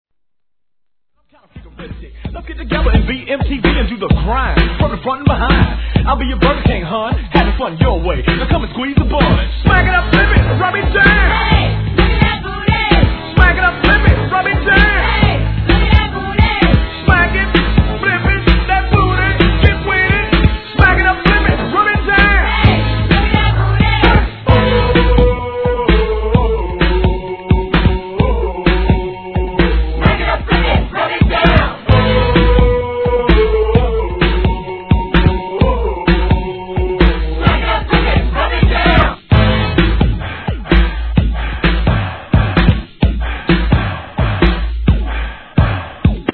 C / 盤面キズ多いです
HIP HOP/R&B
(129 BPM)